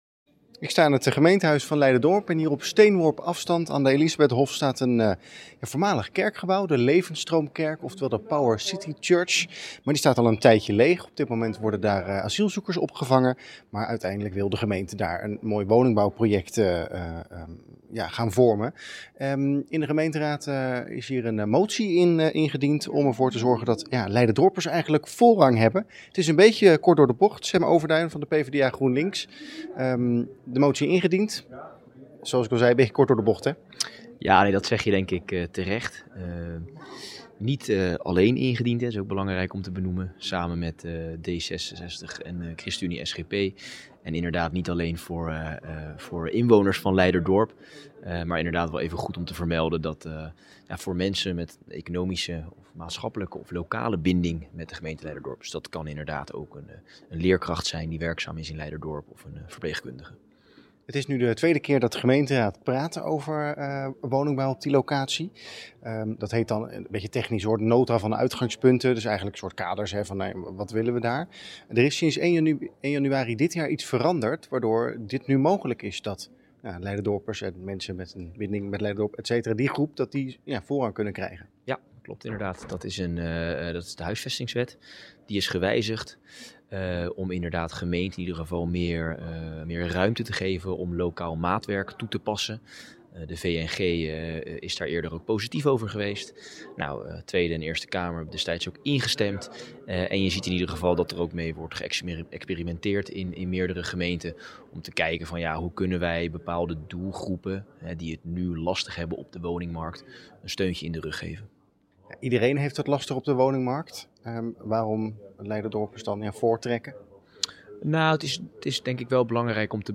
PvdA/GroenLinks raadslid Sem Overduin over de toekomst van de Power City Church.